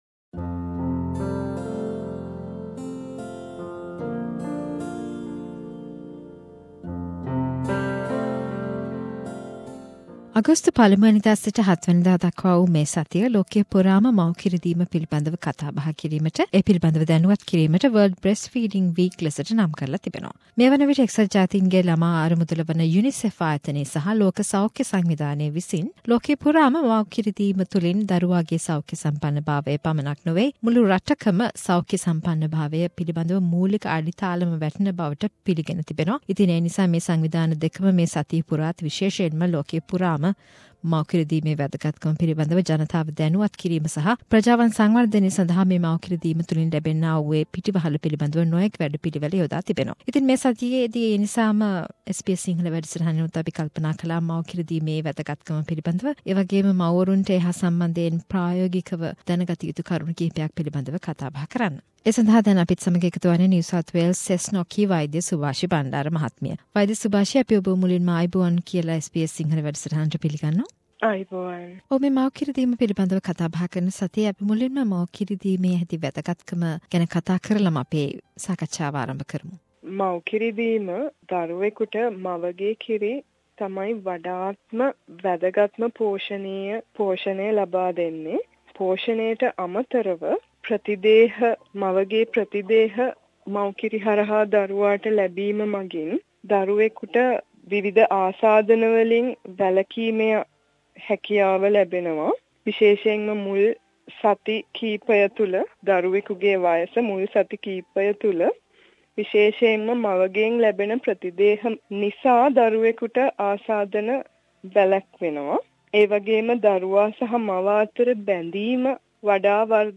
A medical discussion about breastfeeding to mark world breast feeding week.